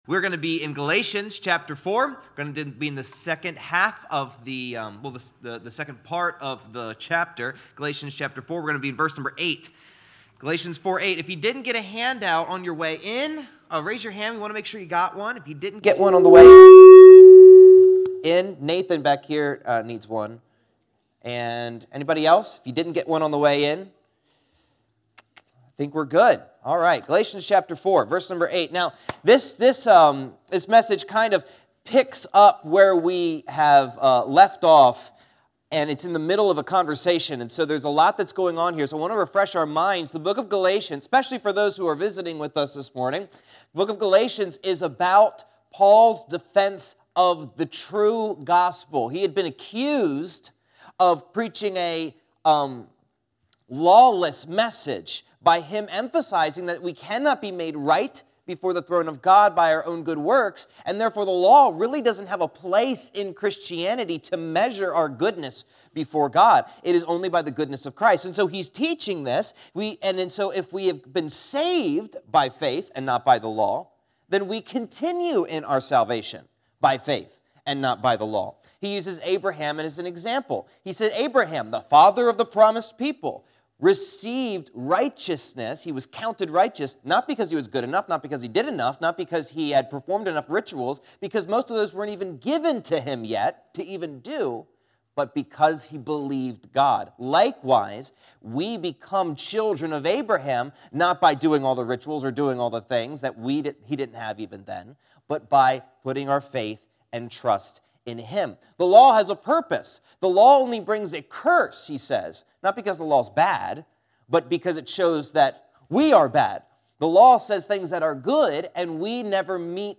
Service Type: Auditorium Bible Class